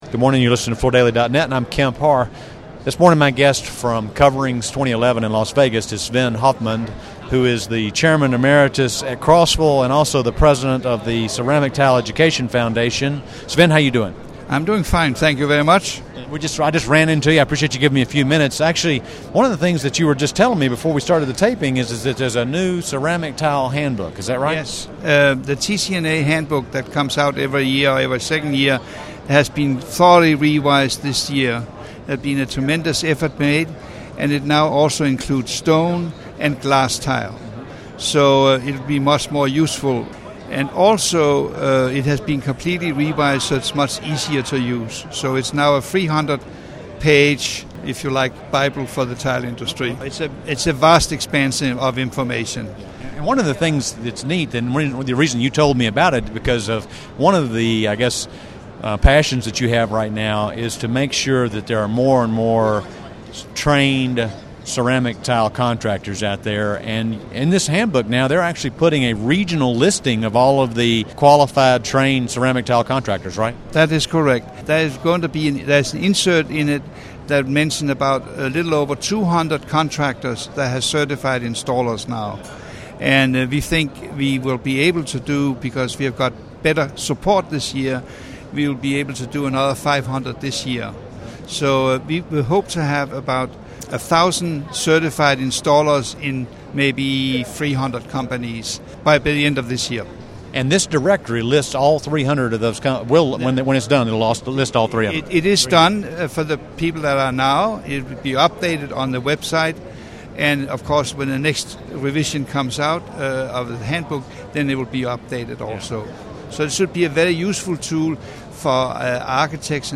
Listen to the interview to hear what's included in the new guide and how the organization hopes to have 1,000 certified installers by the end of the year.